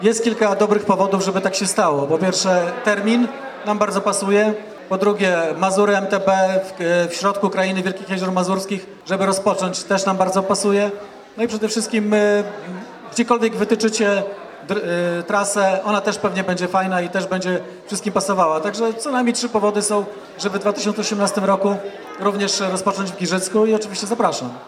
A czy areną przyszłorocznego prologu ponownie będzie Giżycko? Burmistrz Wojciech Iwaszkiewicz twierdzi, ze to bardzo dobry pomysł.